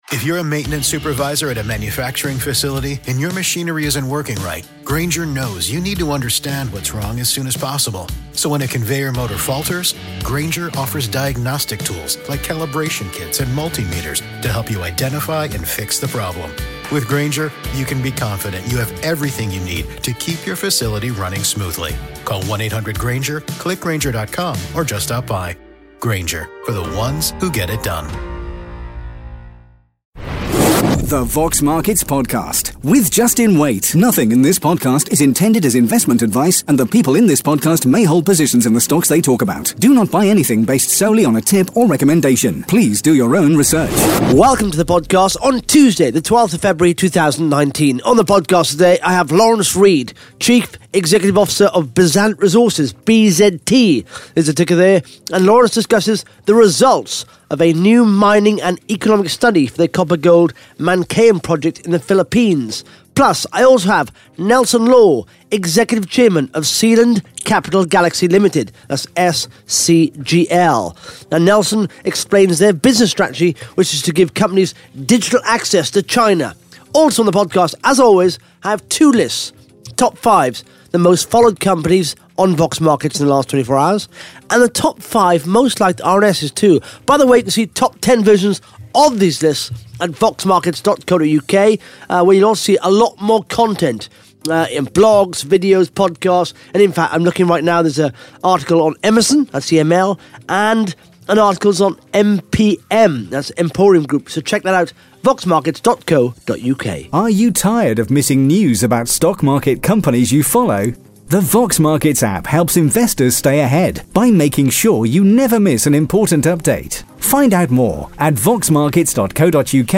(Interview starts at 20 minutes 27 seconds) Plus the Top 5 Most Followed Companies & the Top 5 Most Liked RNS’s on Vox Markets in the last 24 hours.